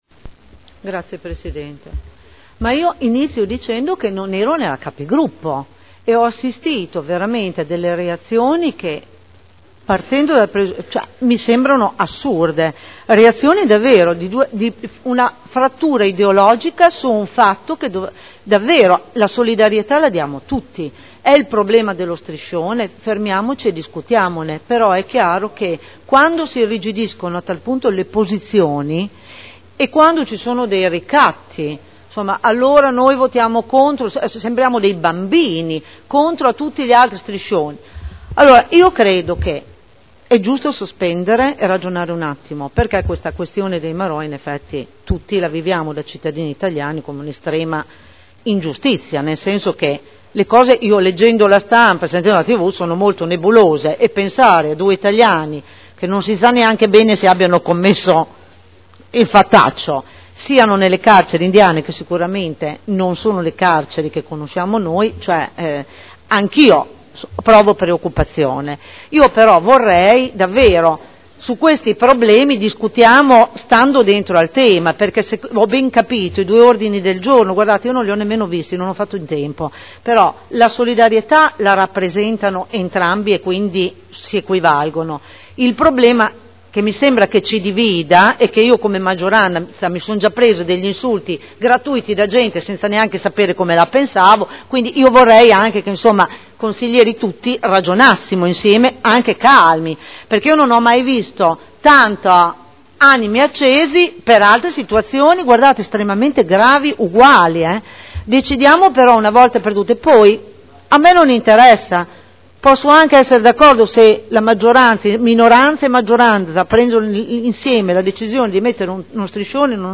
Cinzia Cornia — Sito Audio Consiglio Comunale